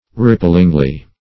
ripplingly - definition of ripplingly - synonyms, pronunciation, spelling from Free Dictionary Search Result for " ripplingly" : The Collaborative International Dictionary of English v.0.48: Ripplingly \Rip"pling*ly\, adv. In a rippling manner.
ripplingly.mp3